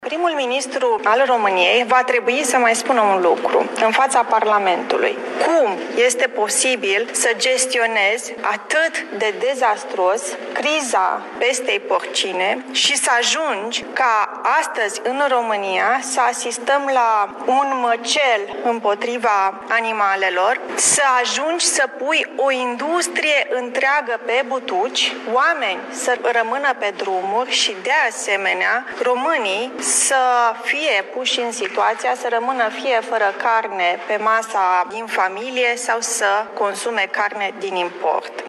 Liderul deputaţilor liberali, Raluca Turcan a declarat astăzi:
29-august-Raluca-Turcan.mp3